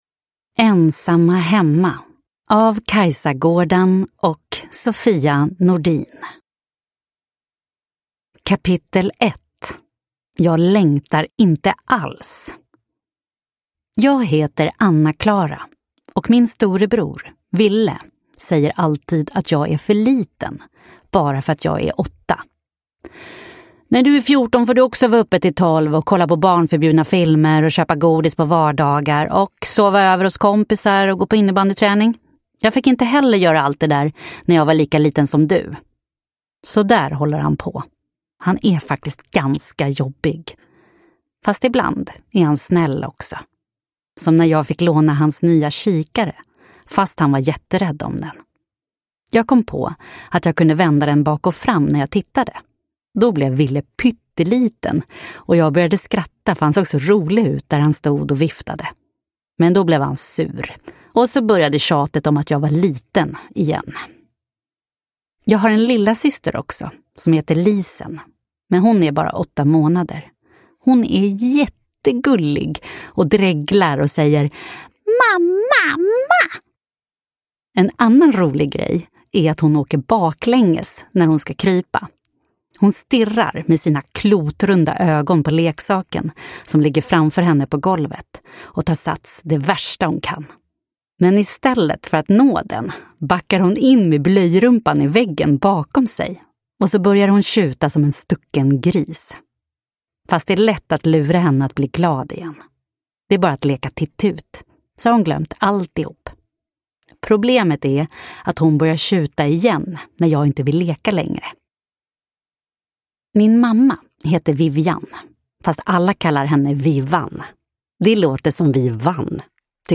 Ensamma hemma – Ljudbok – Laddas ner